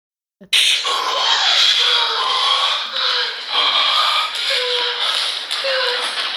Gemidos 8888
gemidos-8888.mp3